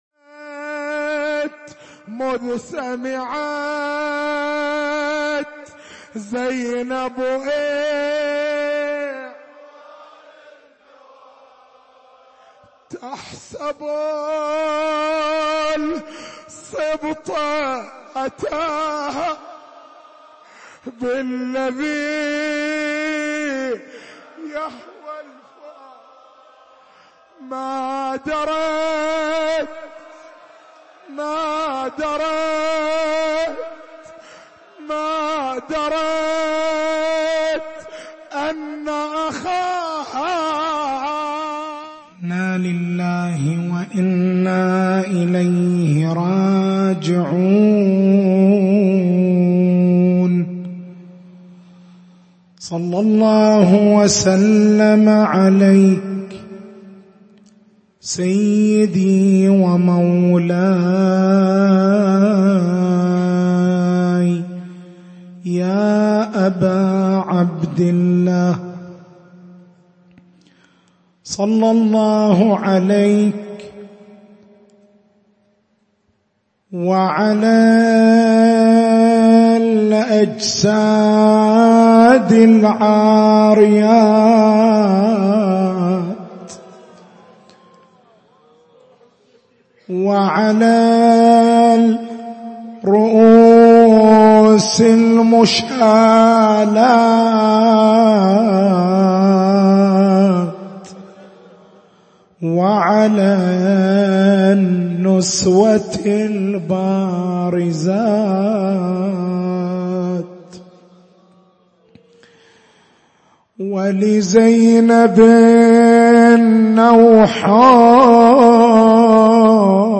تاريخ المحاضرة: 11/01/1439 نقاط البحث: سلب الأجساد الشريفة تسابق القوم إلى جريمة السلب تفاصيل سلب الجسد الشريف هل اختصّ السلب بجسد سيّد الشهداء (ع) أم شمل الأجساد الأخرى؟
حسينية عمارة بالربيعية